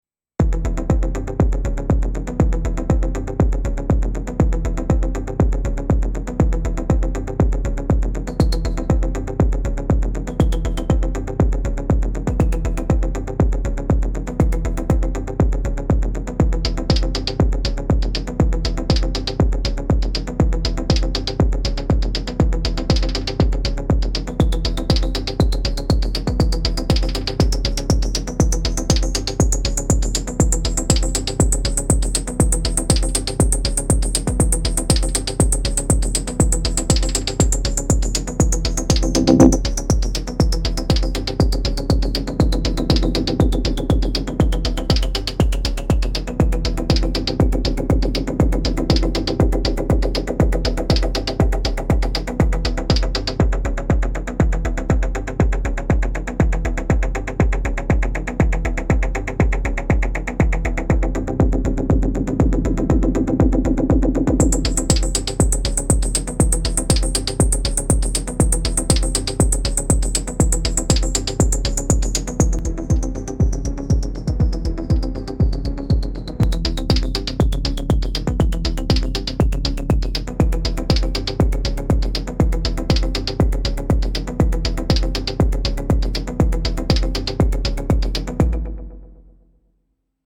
Some moving bass drums and vaguely tonal stuff from the UT Impulse machines.
Added CY Ride on track 12.
Bass coming from HH Lab, the rest is UT Imp kicks and UT Noise hihats.
Single pattern with some simple live routing, delay feedback and muting.